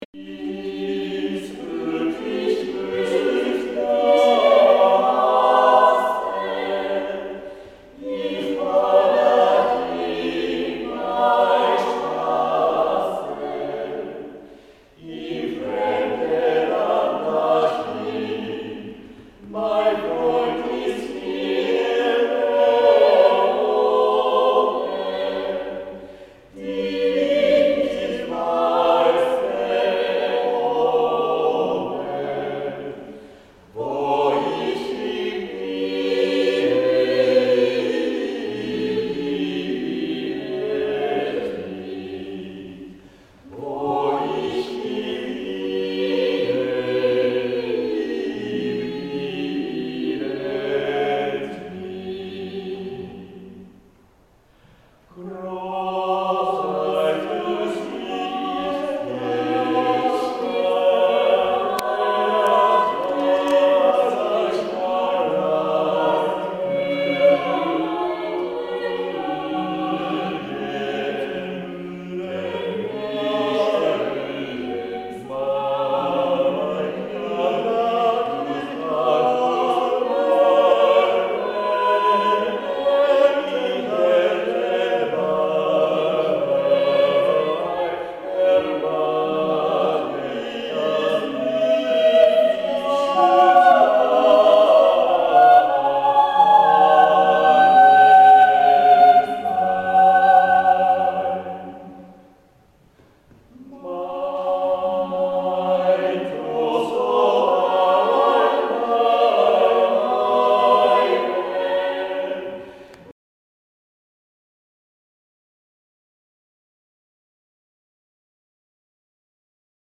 独歌
折しも博物館明治村の学習院長官の建物の修復が終了したので記念にドイツの歌の披露がザビエル天主堂であった。
これを期待して出かけたが野ばらなどは日本語で歌われた。